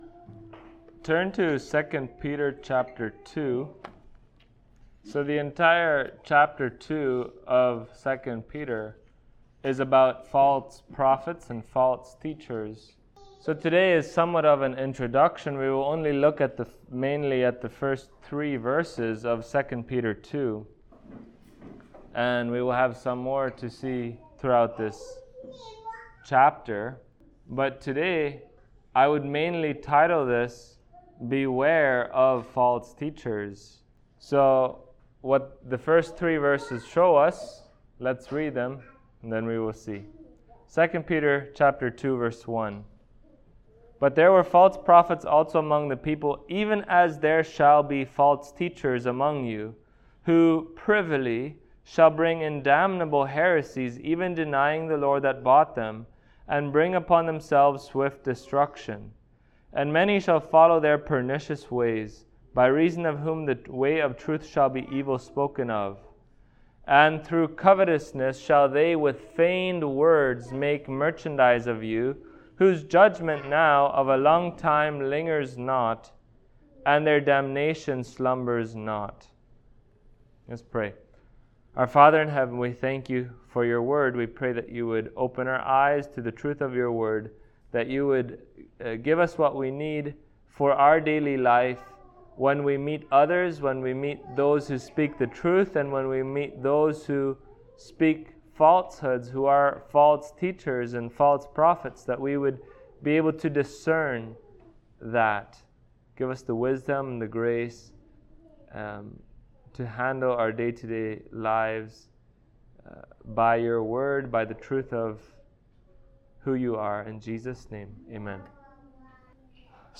2 Peter Passage: 2 Peter 2:1-3 Service Type: Sunday Morning Topics